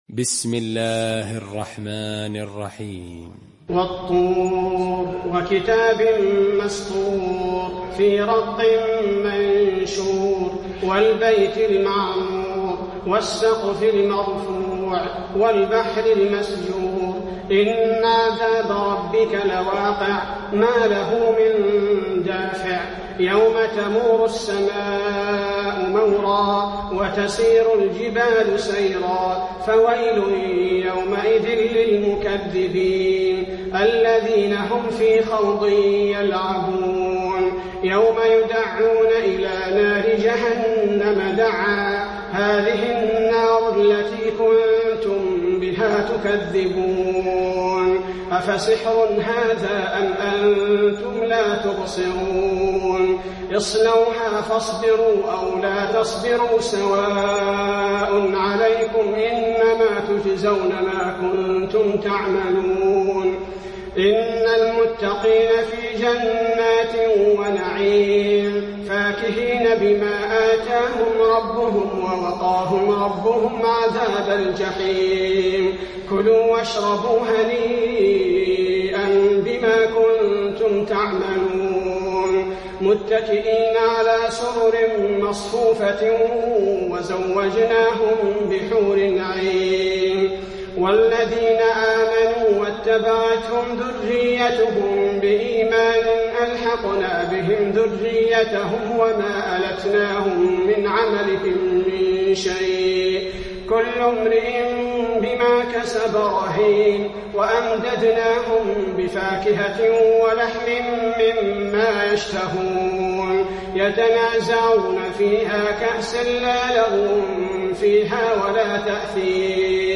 المكان: المسجد النبوي الطور